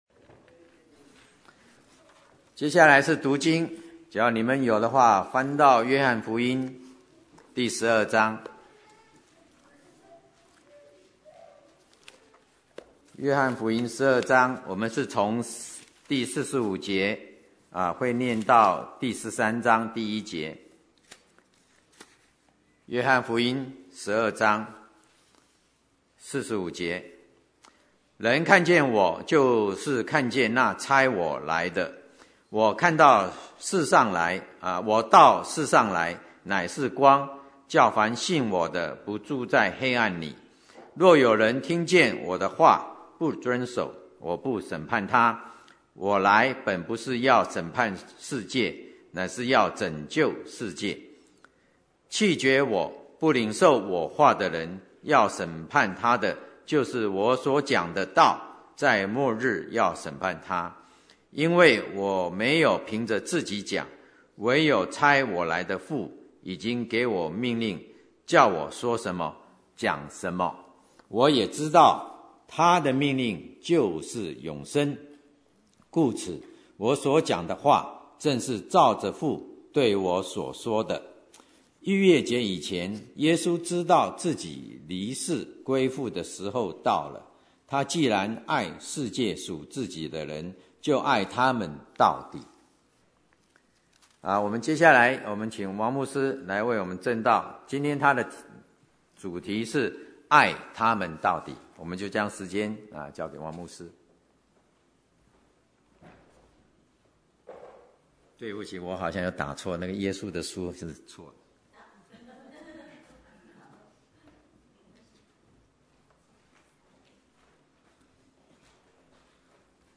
Bible Text: 約翰福音12:45-13:1 | Preacher